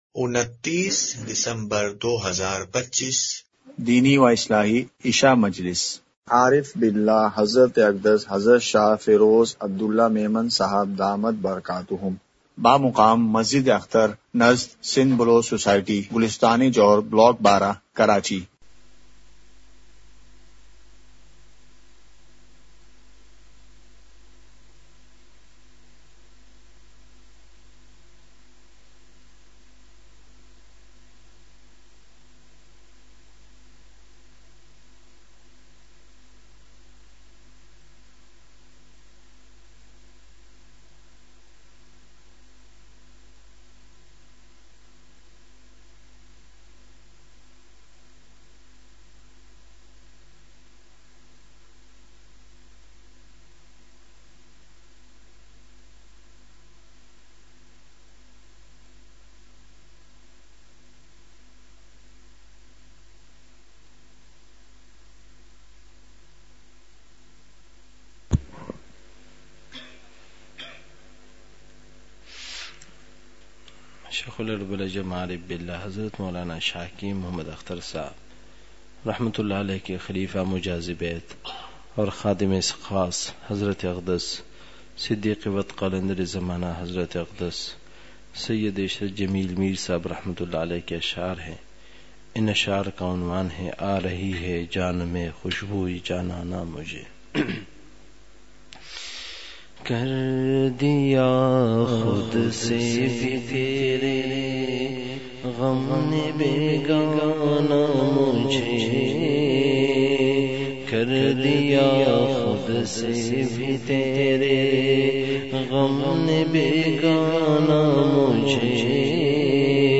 *مقام:مسجدِ اختر نزدسندھ بلوچ سوسائٹی گلستانِ جوہر بلاک12کراچی*
*بیان کے آغاز میں اشعار کی مجلس ہوئی۔۔*